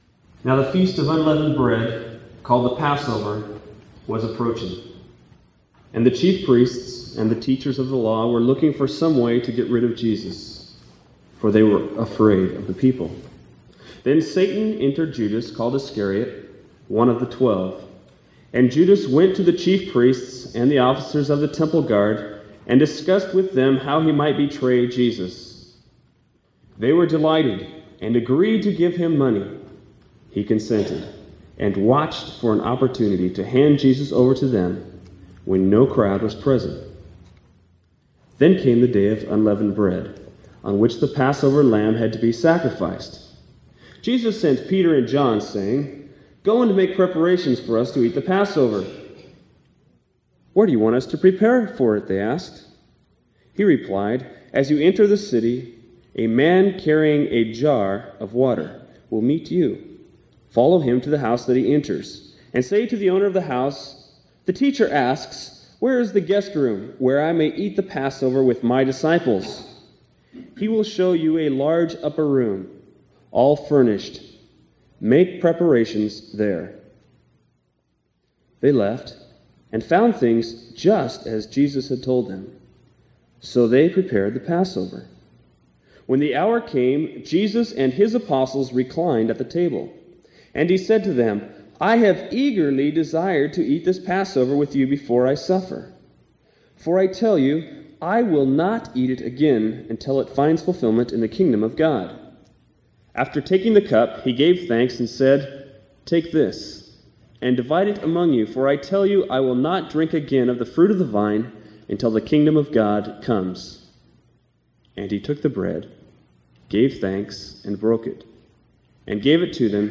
Good Friday Special Service – 0986AB